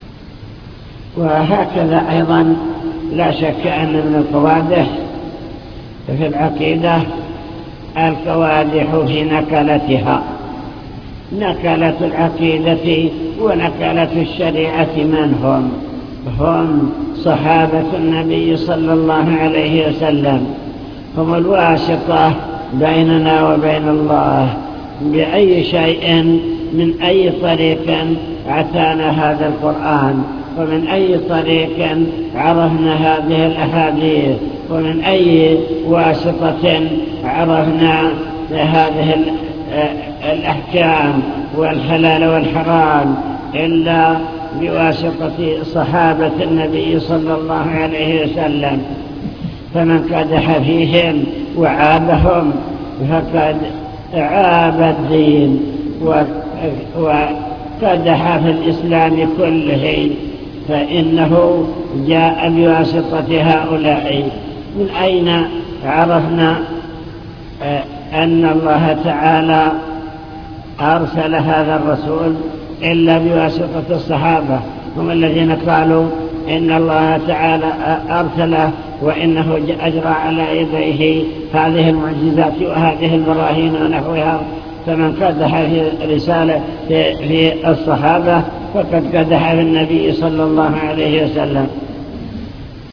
المكتبة الصوتية  تسجيلات - محاضرات ودروس  قوادح في العقيدة